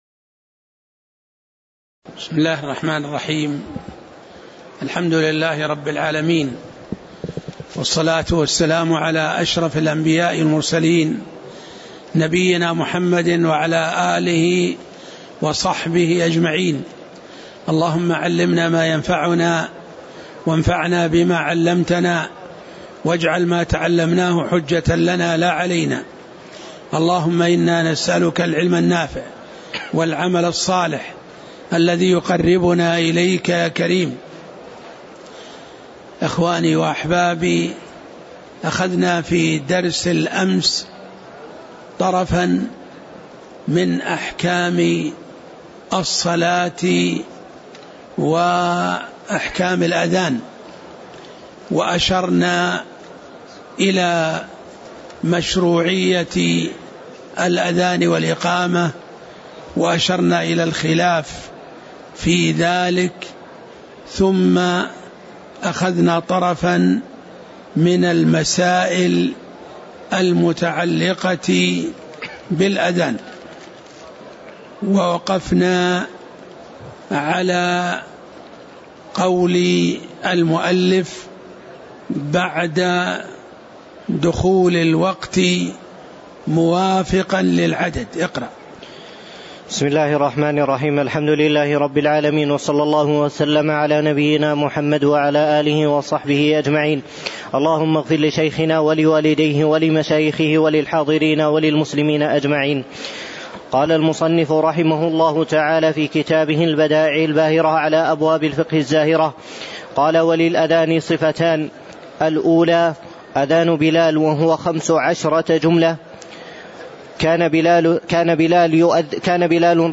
تاريخ النشر ١٣ صفر ١٤٣٨ هـ المكان: المسجد النبوي الشيخ